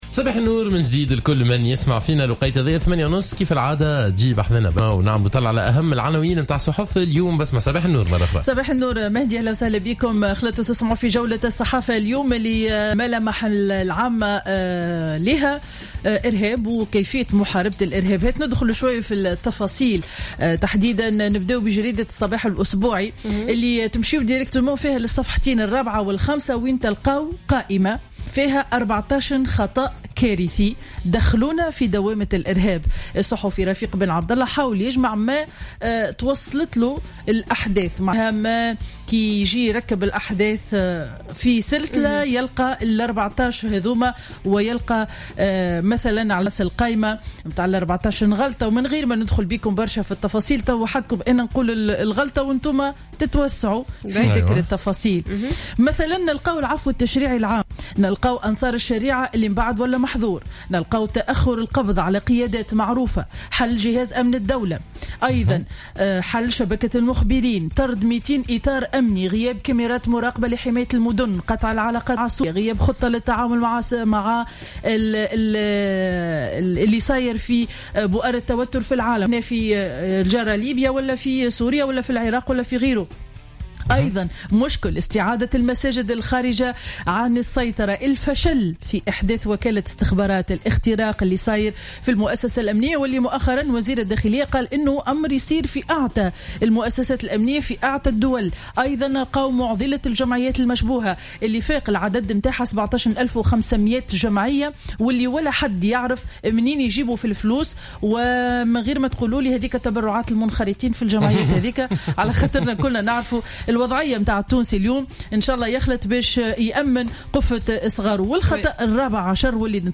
Revue de presse du lundi 30 novembre 2015